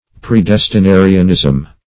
\Pre*des`ti*na"ri*an*ism\